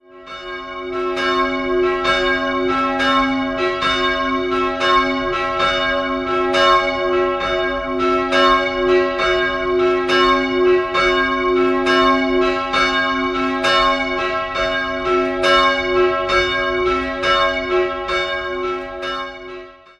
Somit musste St. Hippolyt über 70 Jahre ohne Turm auskommen, die beiden Glocken hingen in dieser Zeit läutbar im Dachstuhl. 2-stimmiges Kleine-Terz-Geläute: cis''-e'' Die größere Glocke wurde 1958 von Georg Hofweber in Regensburg gegossen, die kleinere stammt von Johann Silvius Kleeblatt (Amberg) und entstand 1764.